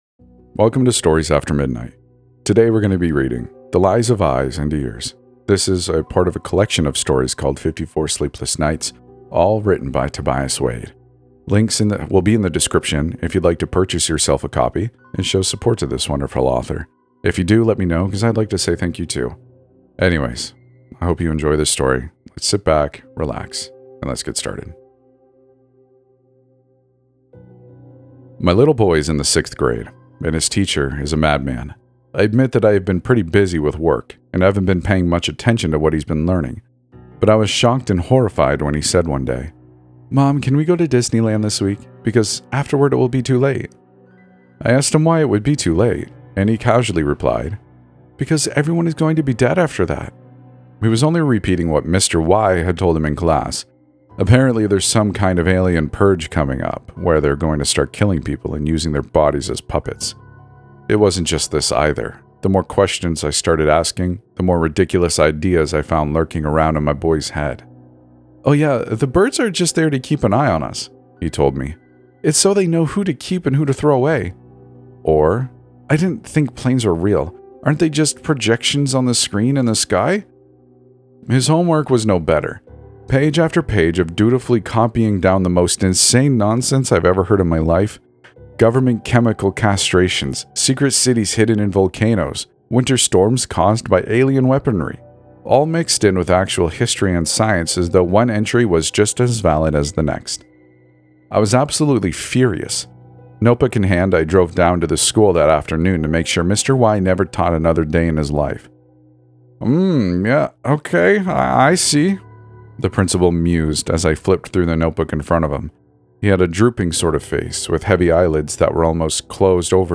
"The Midnight Podcast" is a show dedicated to true and fictional stories.